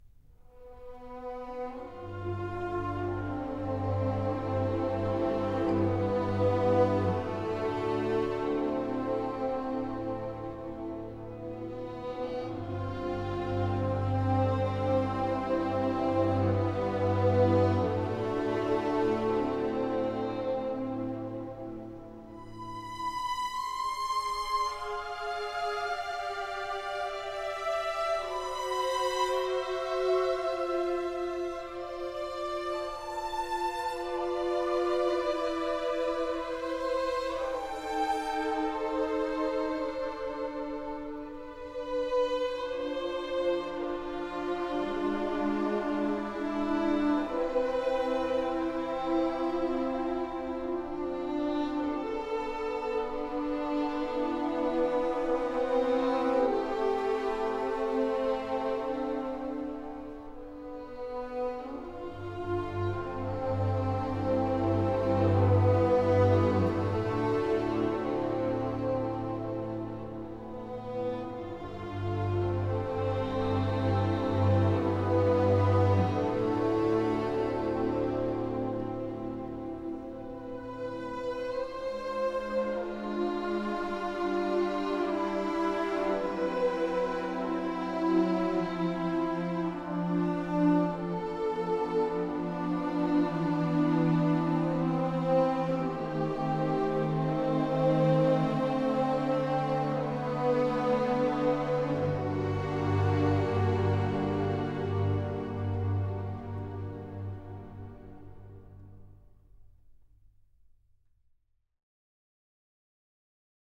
emotional, yearning, sighing strings